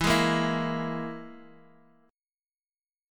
EMb5 chord